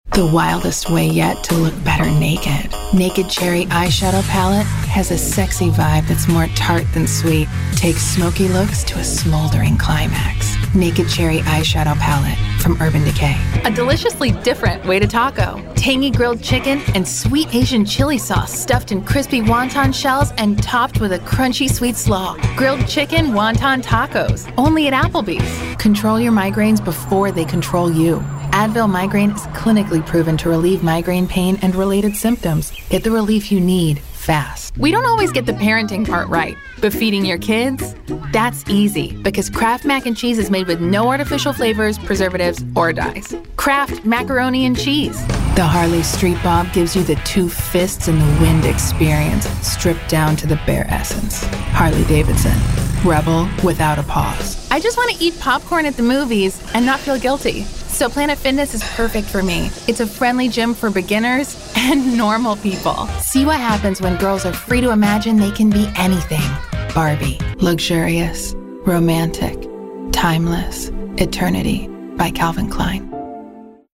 Warm, conversational, and friendly Luxurious, rich, classy and exclusive
Slightly raspy, edgy, and smart
Accents: standard us
VOICEOVER GENRE commercial